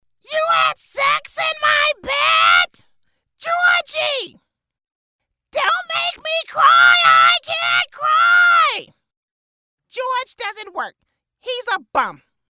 Female
My voice is authentic, smooth and can be raspy at times. It is professional, articulate, trustworthy, assertive, warm, inviting and believable. It's also quite soothing.
Impersonations